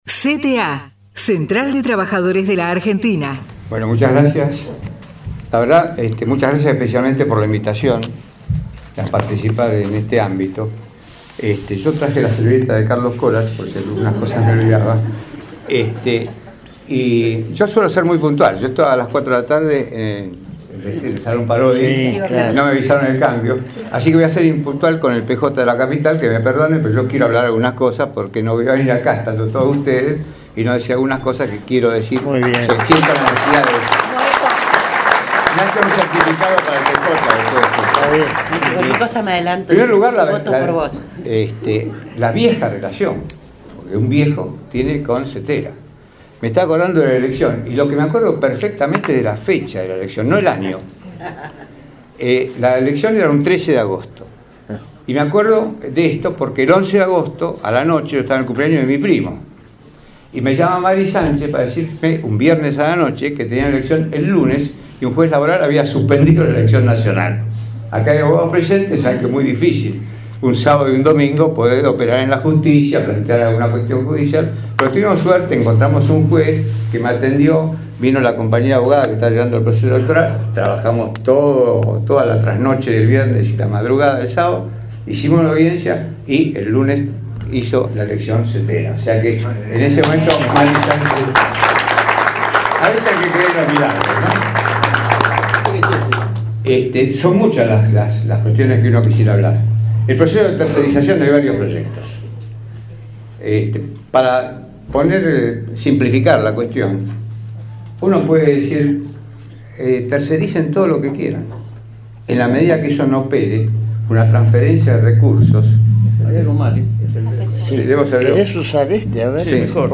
HÉCTOR RECALDE - AUDIENCIA PÚBLICA y RADIO ABIERTA en el CONGRESO NACIONAL
Diputado Nacional - Frente para la Victoria